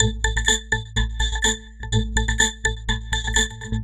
tx_perc_125_resonator_A1.wav